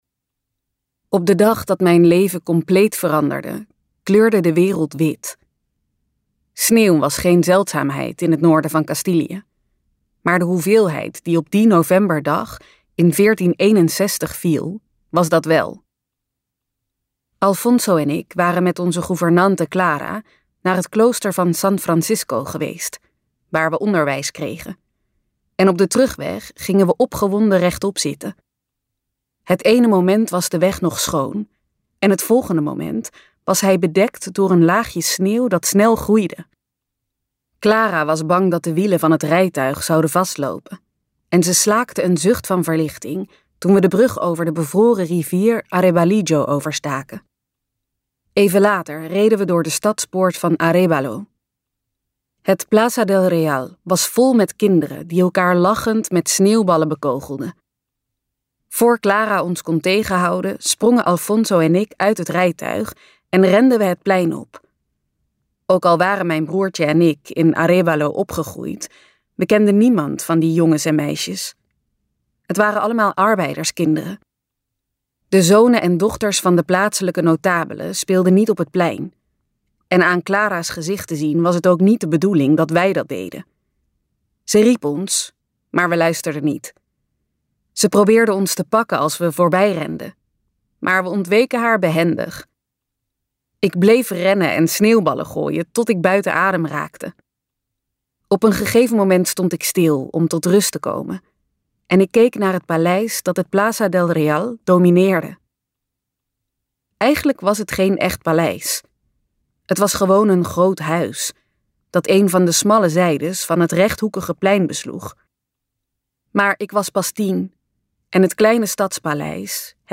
Ambo|Anthos uitgevers - Kruistocht van een koningin luisterboek